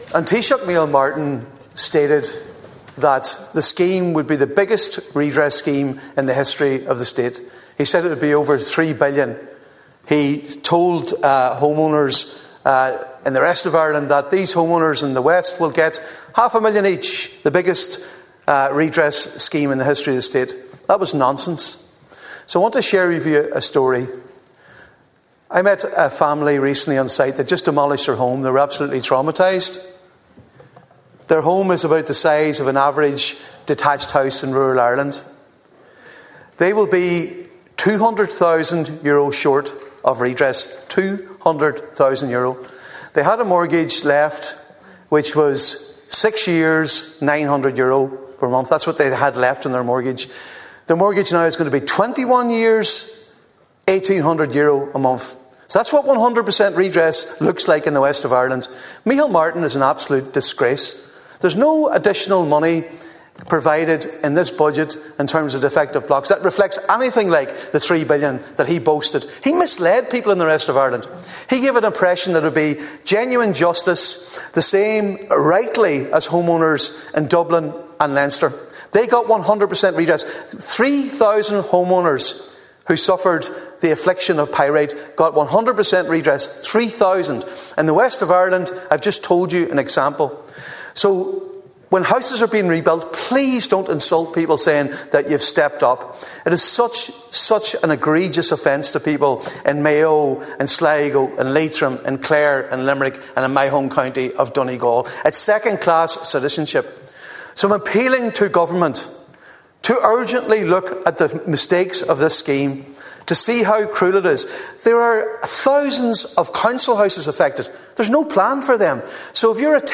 Speaking during the budget debate, Deputy Padraig Mac Lochlainn said the Taoiseach has promised 100% Redress through what would be a three million euro scheme, the biggest ever seen in the state.